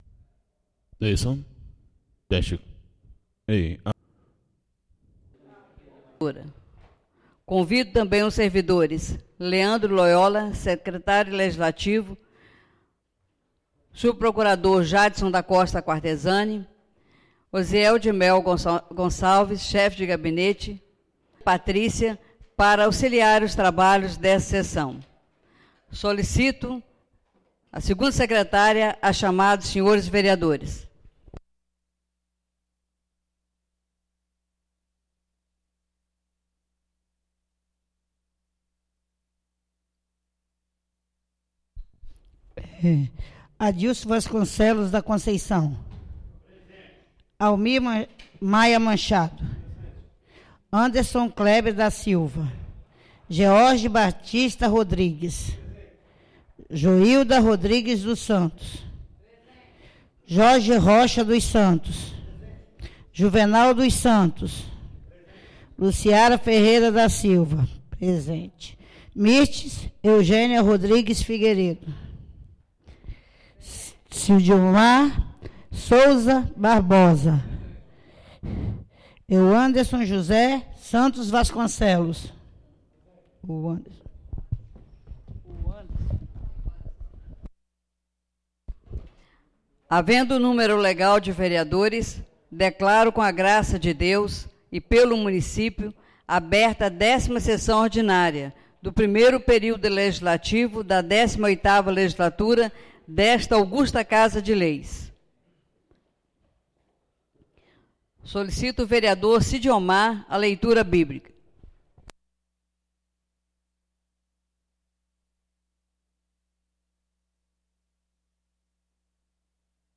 10ª (DÉCIMA) SESSÃO ORDINÁRIA DO DIA 03 DE AGOSTO DE 2017